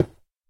sounds / dig / stone3.ogg
stone3.ogg